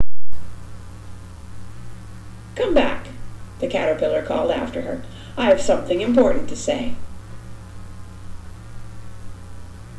在以下所有录音中、我将麦克风放置在距离显示器约6英寸的位置。
我还在听那个"数字"听起来的噪音。 总体噪声仍然非常明显。